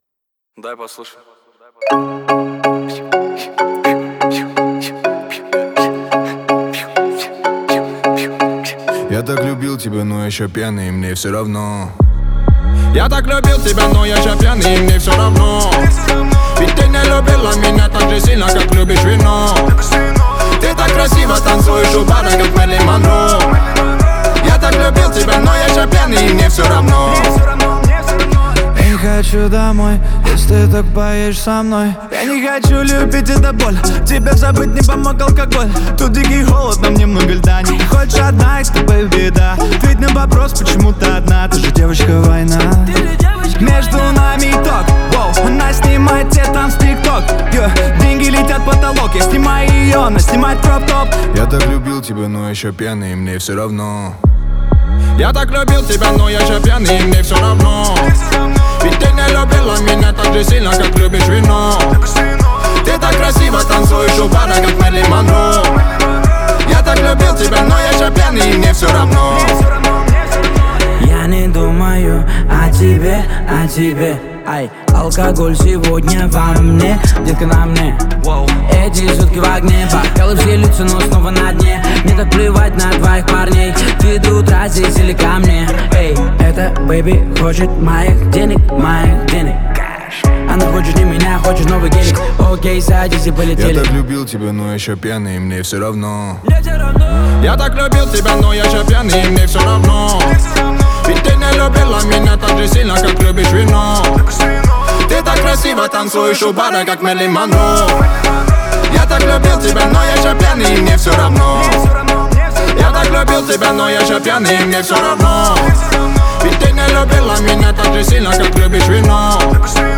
это яркая и запоминающаяся композиция в жанре поп-рэп